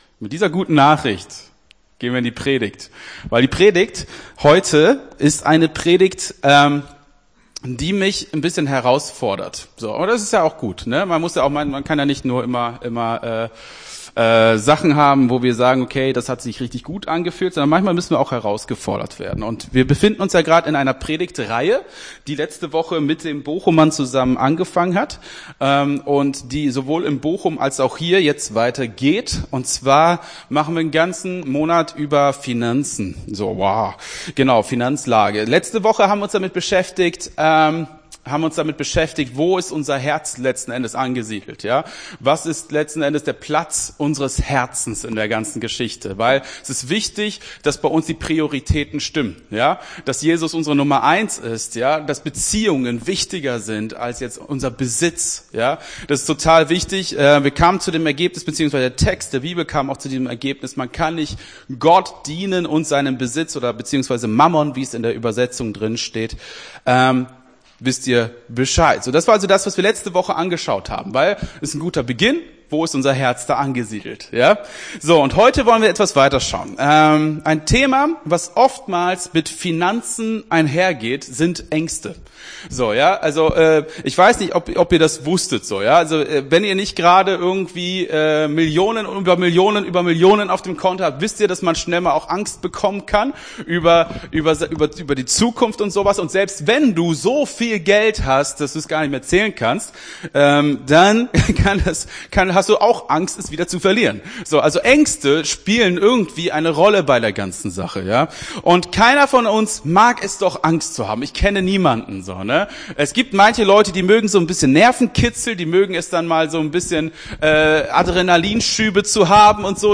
Gottesdienst 09.10.22 - FCG Hagen